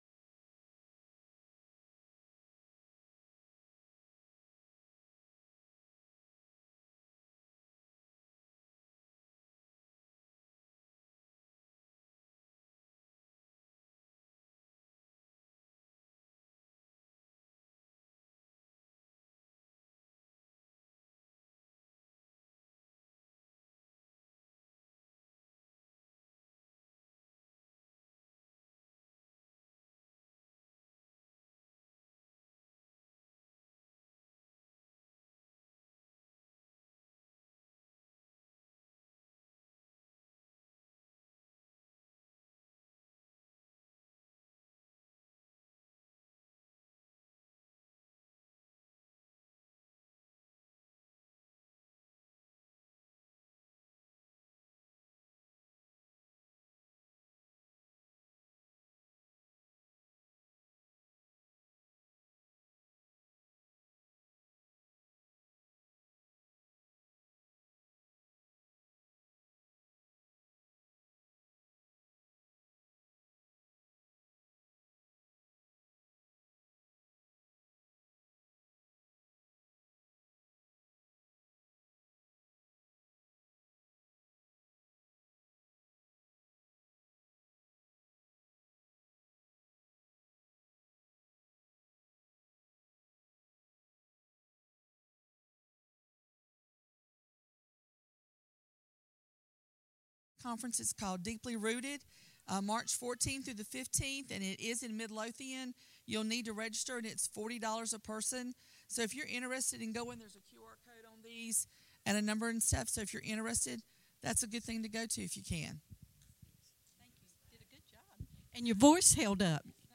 Wednesday Service. live-recording 6/23/2024 9:21:54 AM.